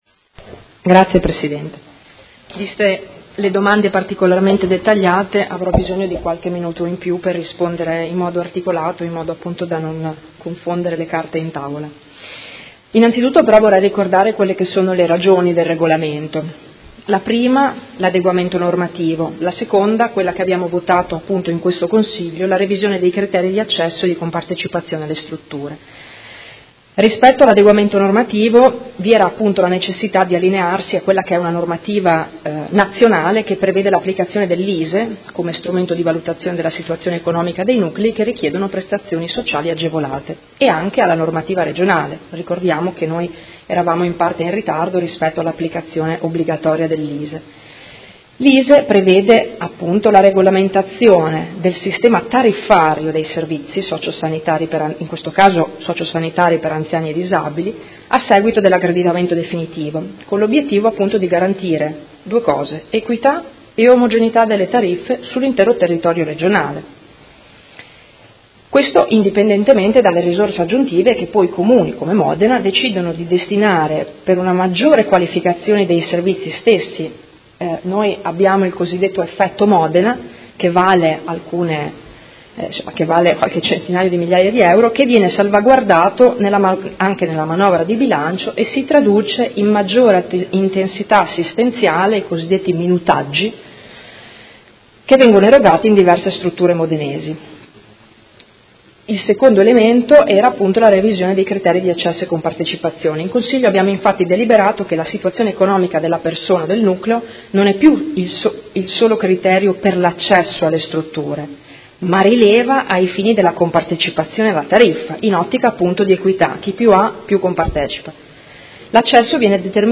Seduta del 01/02/2018 Risponde. Interrogazione del Consigliere Poggi (PD) avente per oggetto: Attuazione nuovo Regolamento per l’accesso alle Case Residenziali e ai Centri Diurni